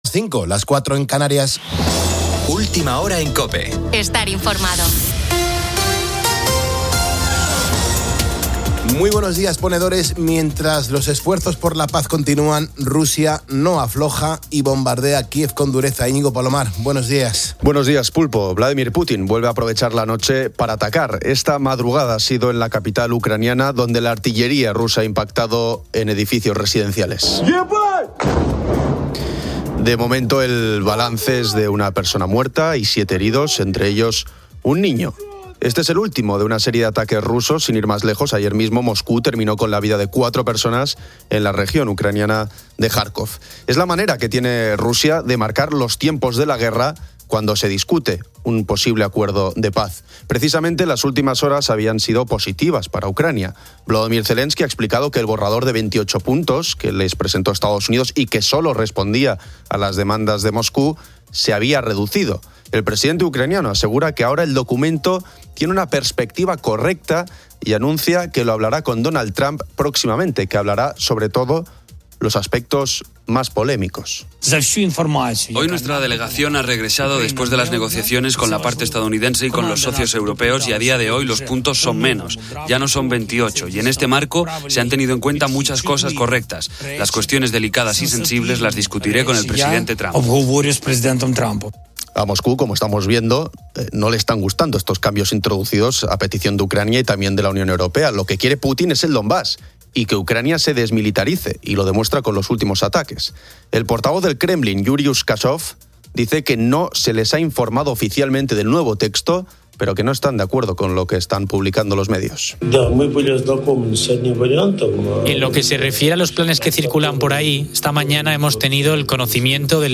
Los oyentes opinan sobre el Black Friday, destacando ofertas y posibles fraudes.
Diversos oyentes comparten sus experiencias laborales nocturnas.